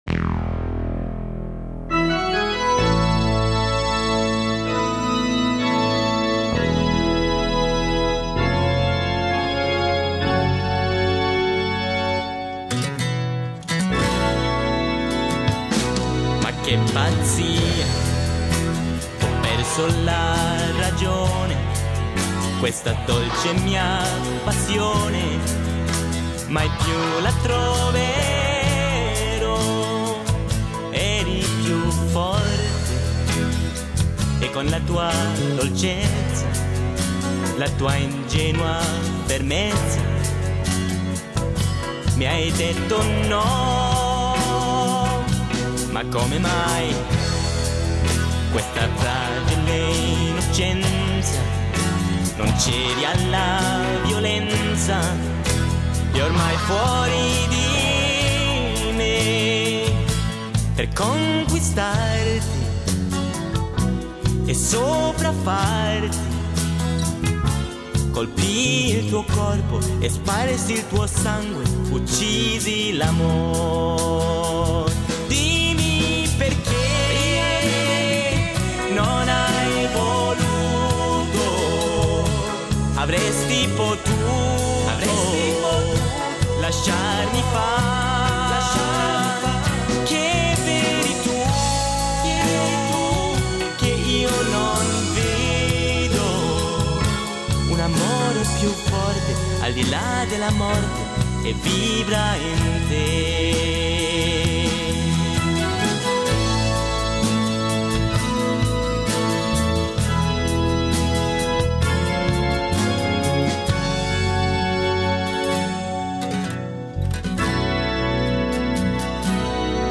Una riflessione quaresimale